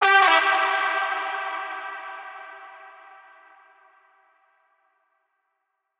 VR_vox_hit_sadstab_E.wav